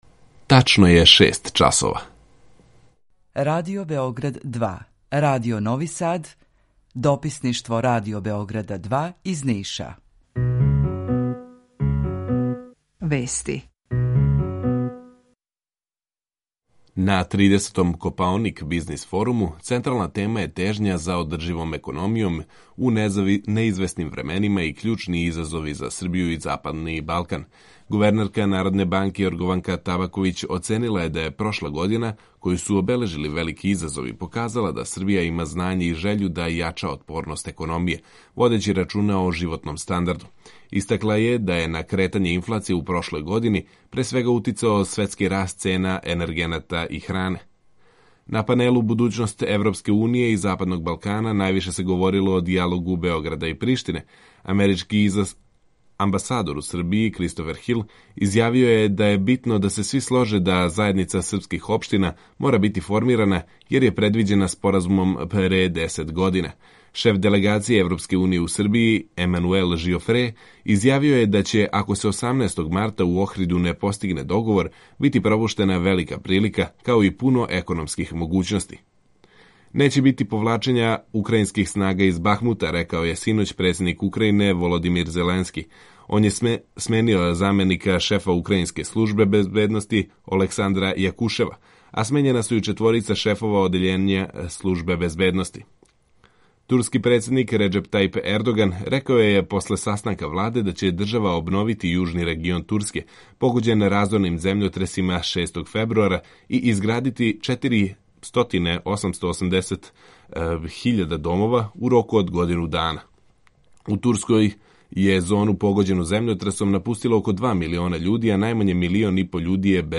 Емисију реализујемо уживо заједно са Радиом Републике Српске у Бањалуци и Радио Новим Садом Јутарњи програм заједнички реализују Радио Београд 2, Радио Нови Сад и дописништво Радио Београда из Ниша.
У два сата, ту је и добра музика, другачија у односу на остале радио-станице.